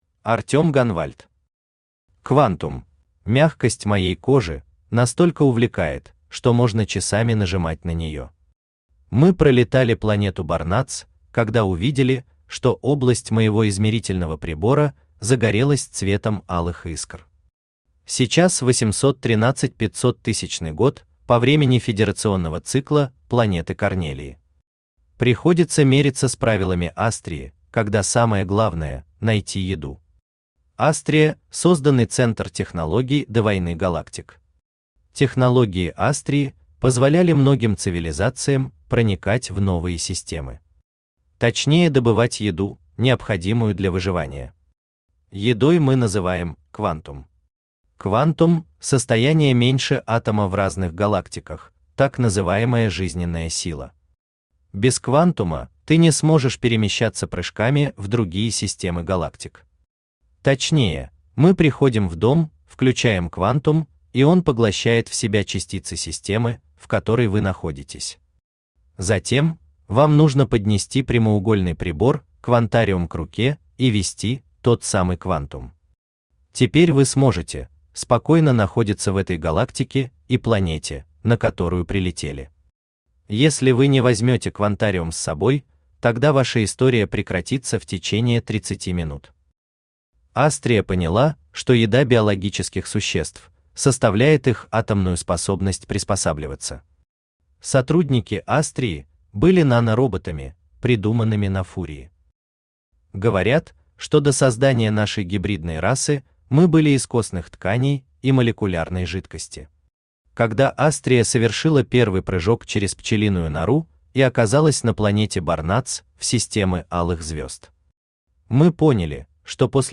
Aудиокнига Квантум Автор Артём Гонвальт Читает аудиокнигу Авточтец ЛитРес.